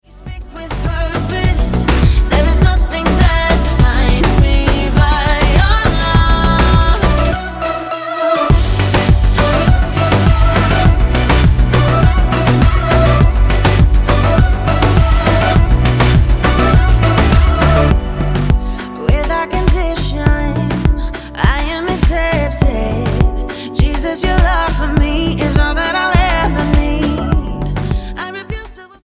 STYLE: Pop
hugely catchy pop clearly targeted at the young